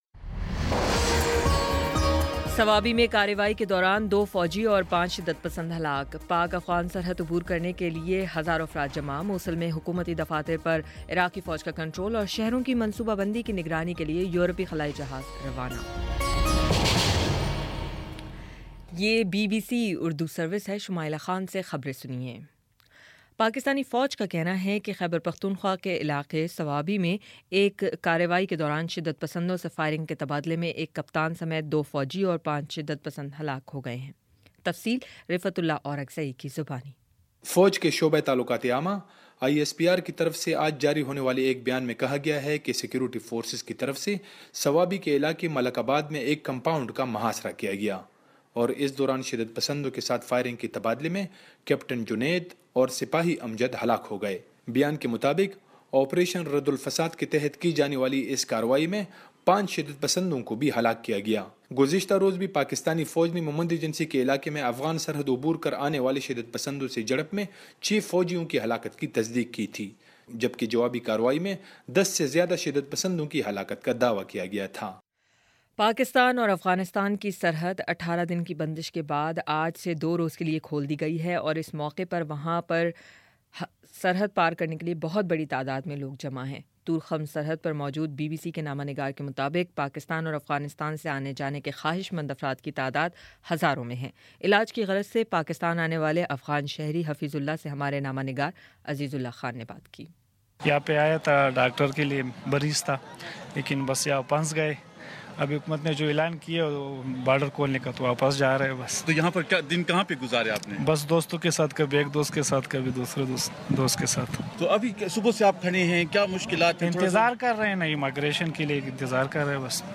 مارچ 07 : شام چھ بجے کا نیوز بُلیٹن